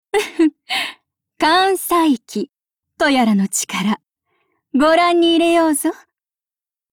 碧蓝航线:女天狗语音